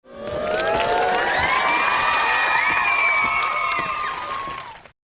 Clapping Esl Sound Effect Download: Instant Soundboard Button